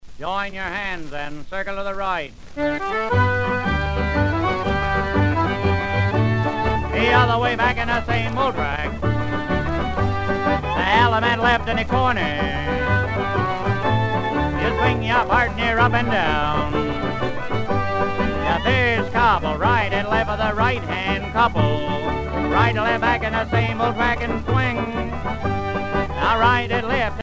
danse : square dance
Pièce musicale éditée